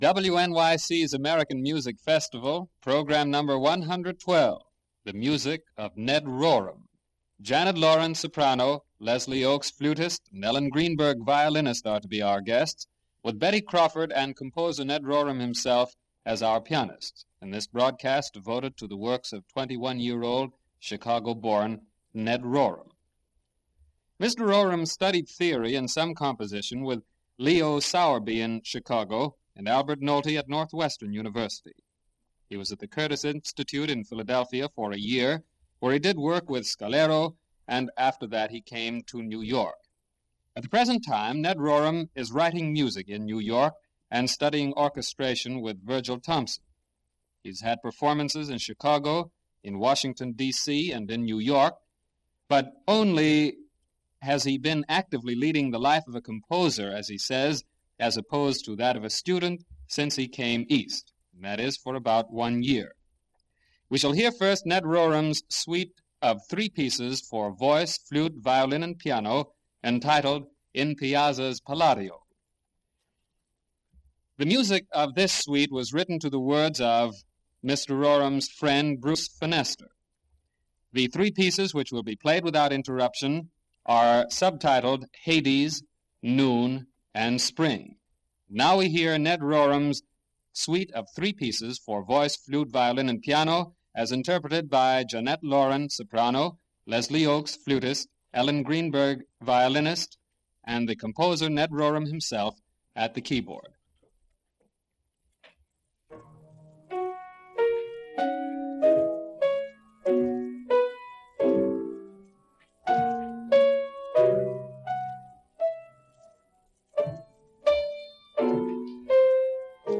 violin
soprano
flute.